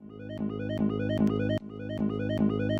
Hip-Hop Vocals Samples
BONUS - FX 1.wav